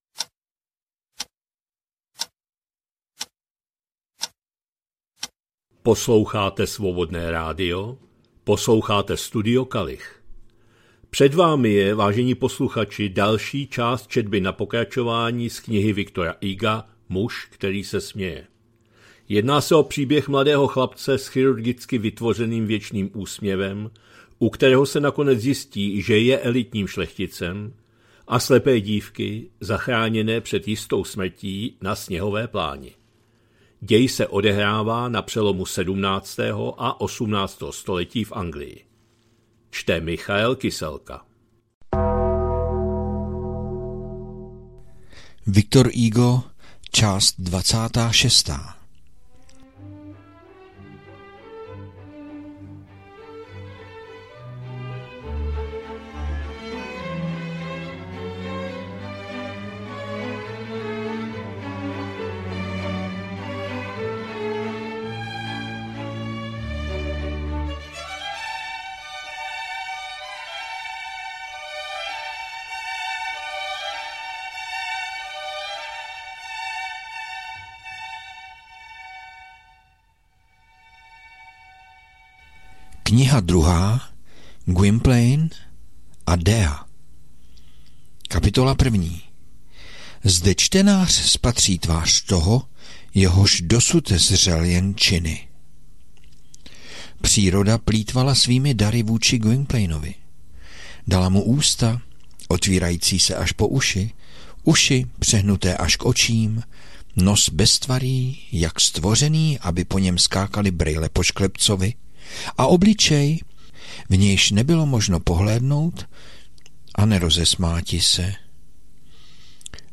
2025-09-08 – Studio Kalich – Muž který se směje, V. Hugo, část 26., četba na pokračování